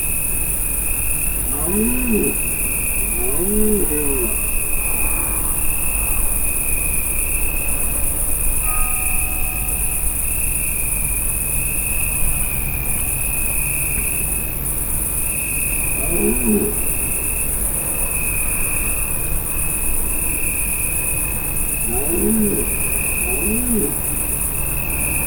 Directory Listing of //allathangok/termeszetben/gimszarvas_professzionalis2015/
magaslesrol_egyetkonduloharang_vizvar00.25.WAV